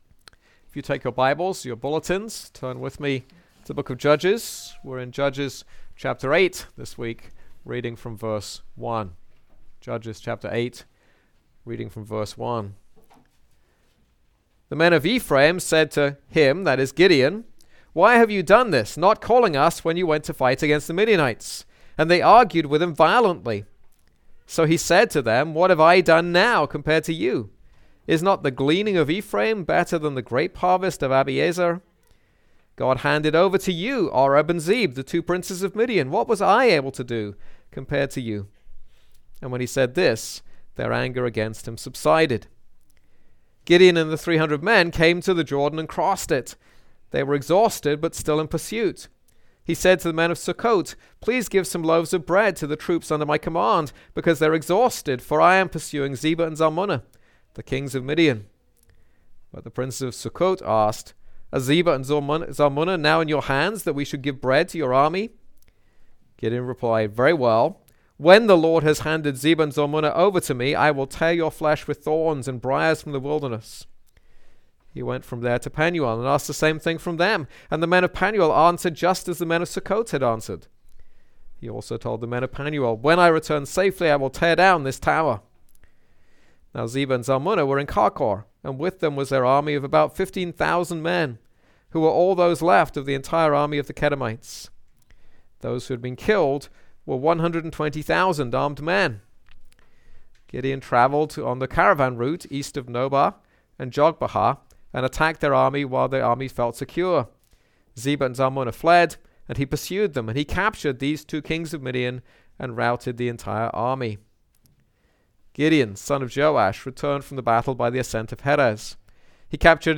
This is a sermon on Judges 8.